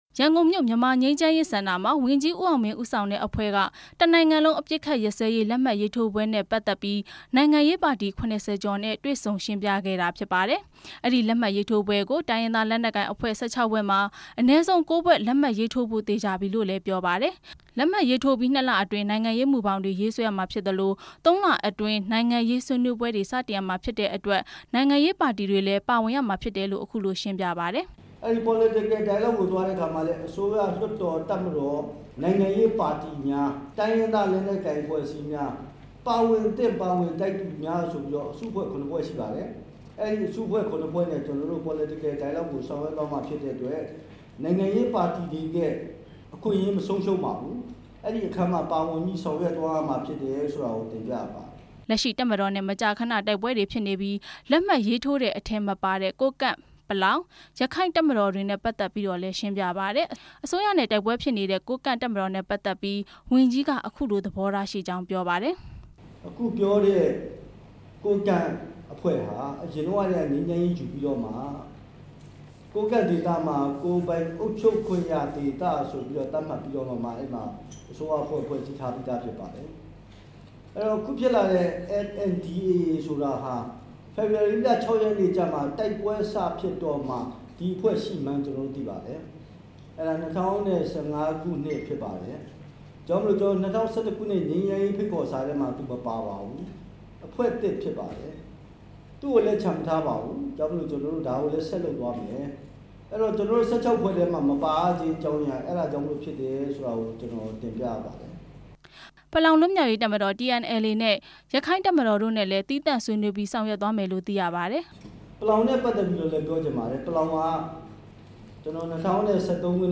ဝန်ကြီး ဦးအောင်မင်းရဲ့ ပြောကြားချက် နားထောင်ရန်